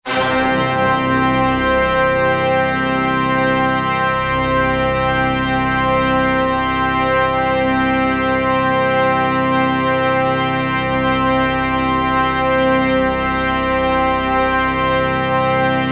描述：木管乐器的旋律
Tag: 120 bpm Orchestral Loops Woodwind Loops 2.71 MB wav Key : Unknown